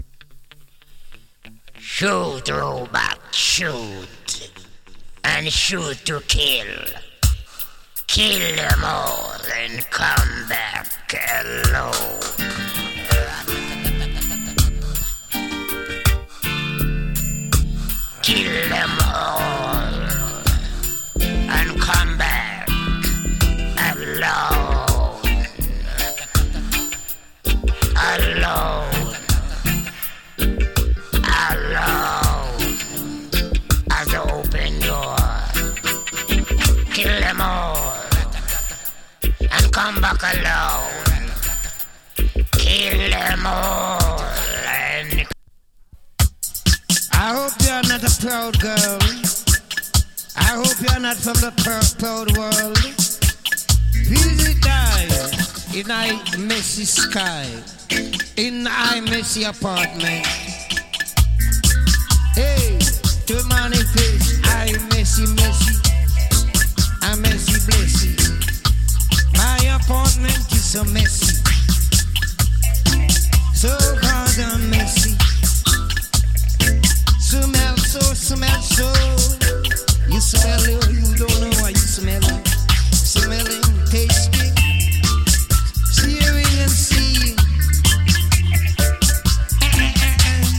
NICE DIGITAL ROOTS TUNE ! LIVE TAKE も有り。